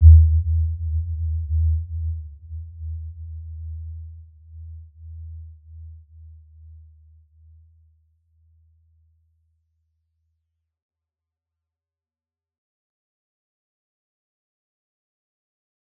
Warm-Bounce-E2-mf.wav